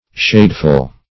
Shadeful \Shade"ful\, a.